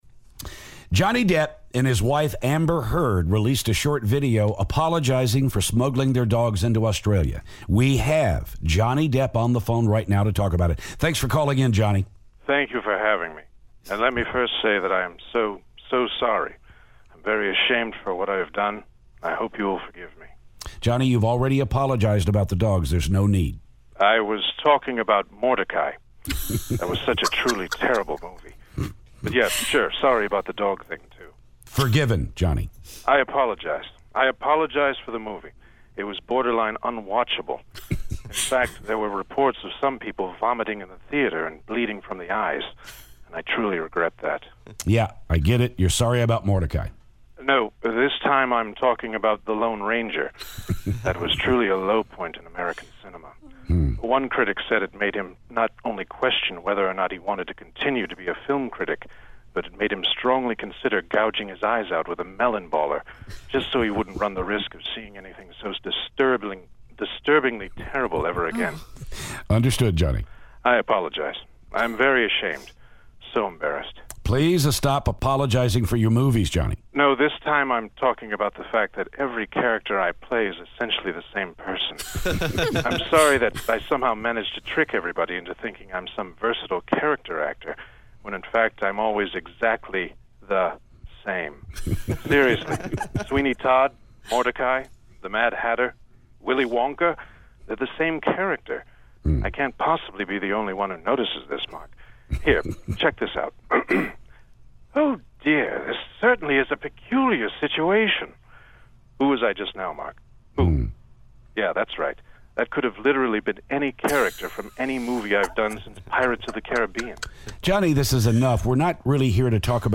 Johnny Depp Phoner
Johnny Depp calls to talk about his apology video to Australia.